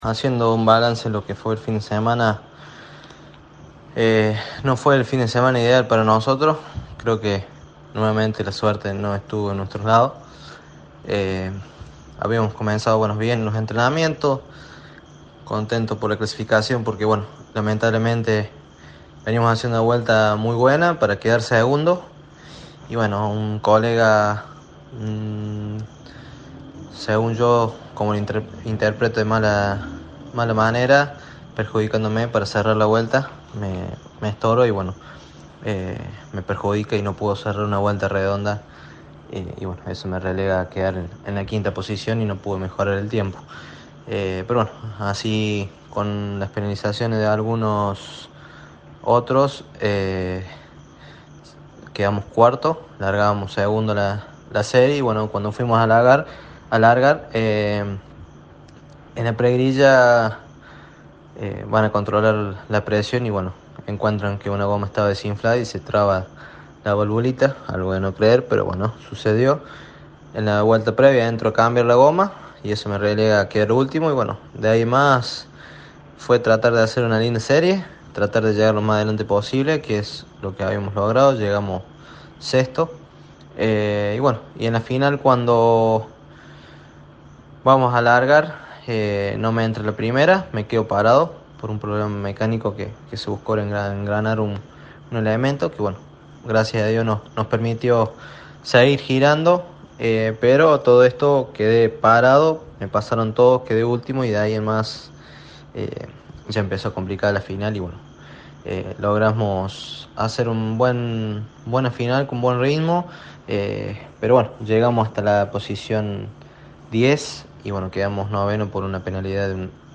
Así se expresaba en diálogo con Poleman Radio: